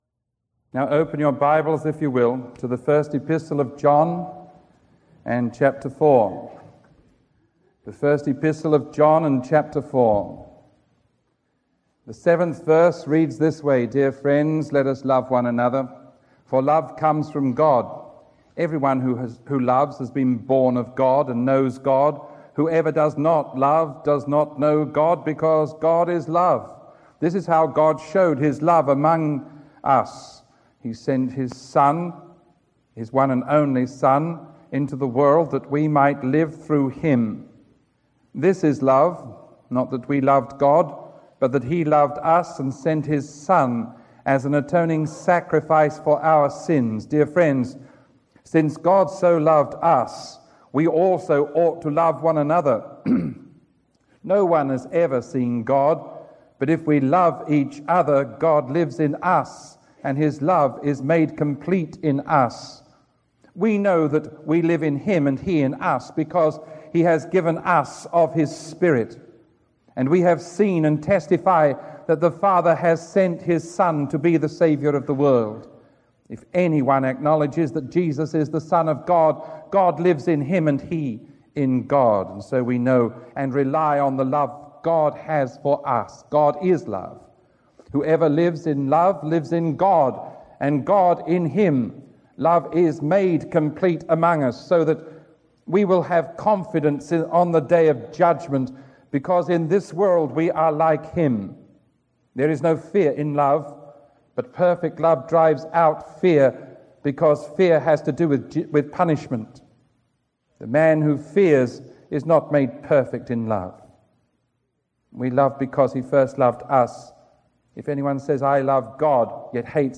Sermon 1110A recorded on August 17